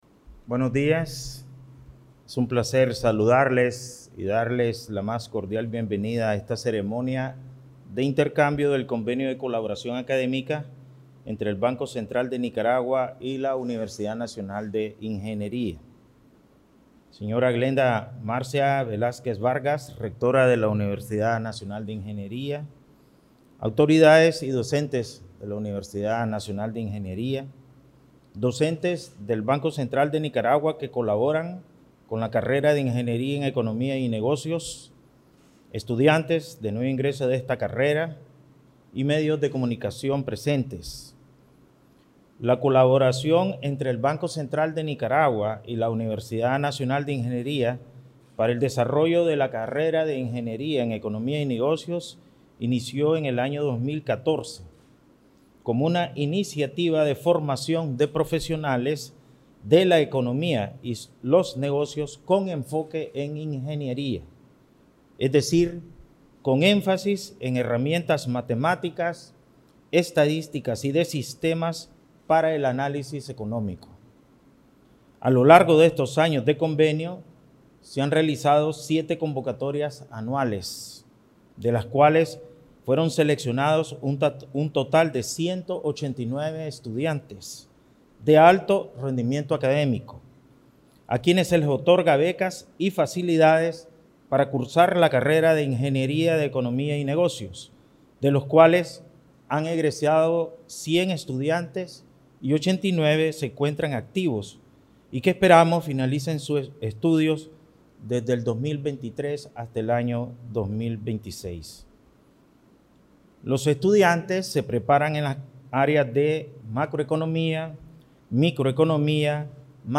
MP3 Palabras del Presidente del BCN, Ovidio Reyes R.